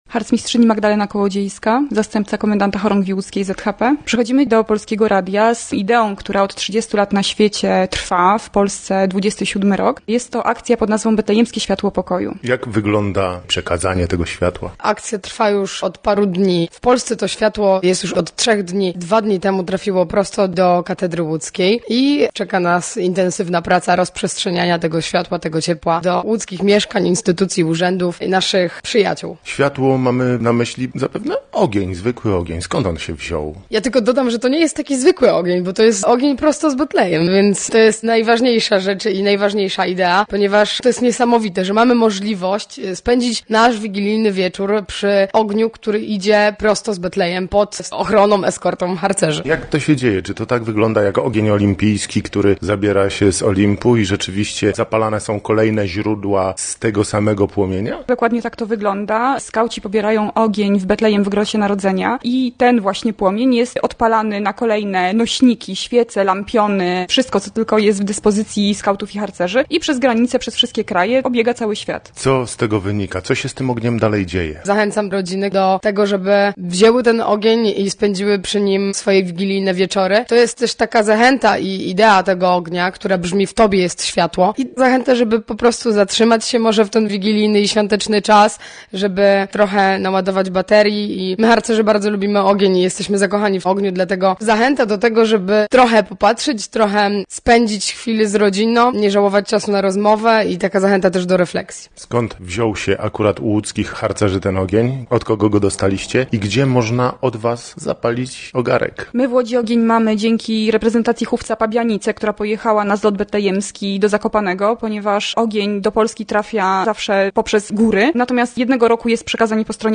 Posłuchaj i dowiedz się więcej: Nazwa Plik Autor Harcerki z Betlejemskim Światlem Pokoju 2017 audio (m4a) audio (oga) Warto przeczytać Zaginął 15-latek z Piotrkowa Trybunalskiego.